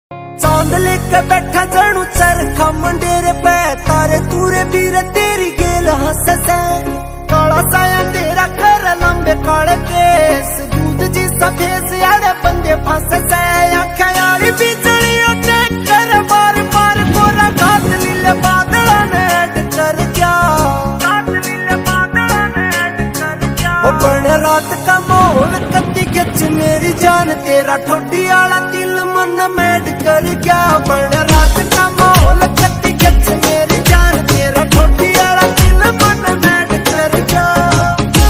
New haryanvi song ringtone